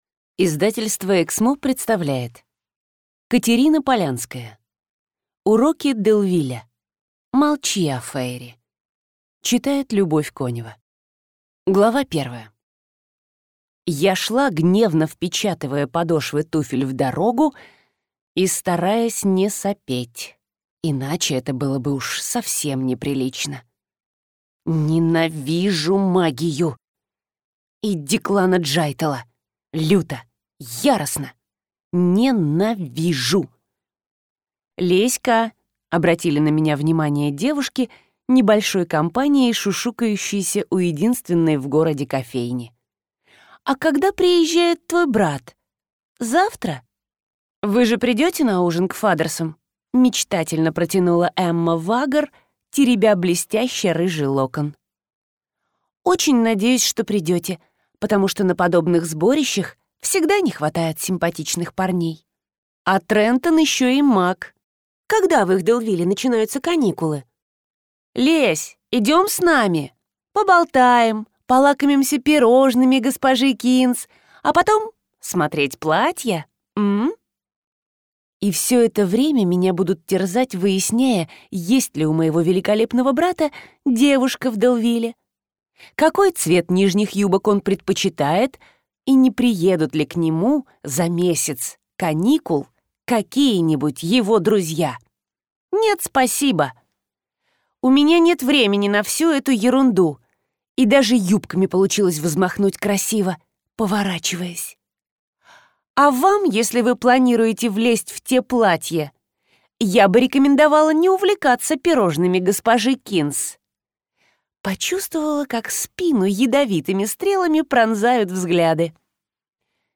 Аудиокнига Уроки Делвиля. Молчи о фэйри | Библиотека аудиокниг